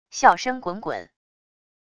笑声滚滚wav音频